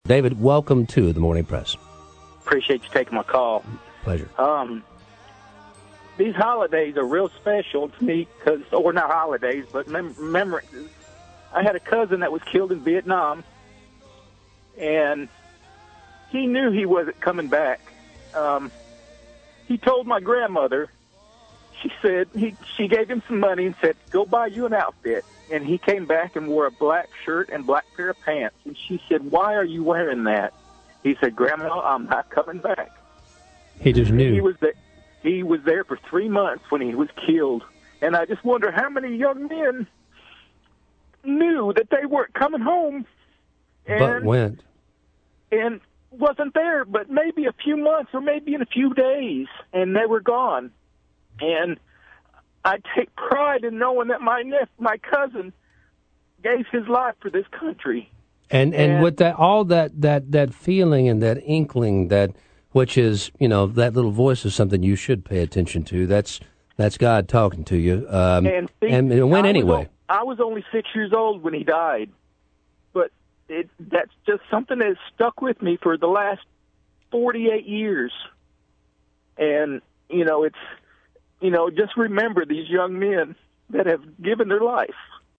poignant Veteran's Day call.